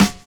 NY 12 SD.wav